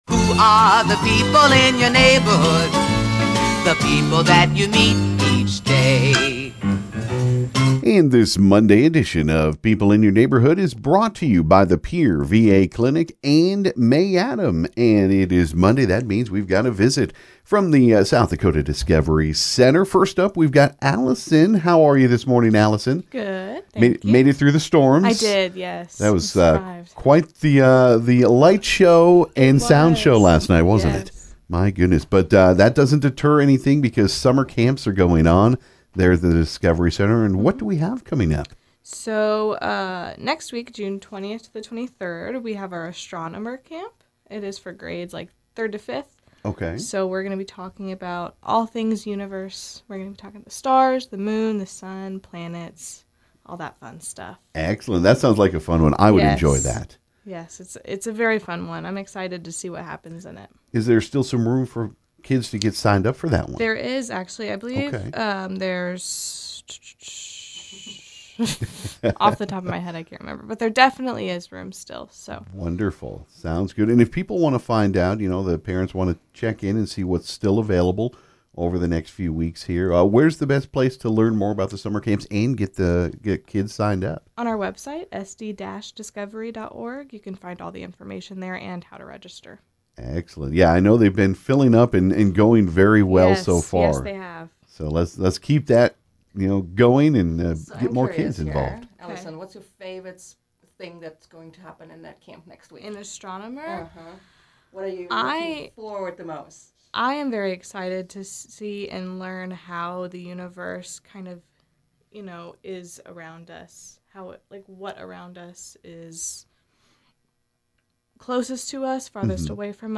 dropped into the KGFX studio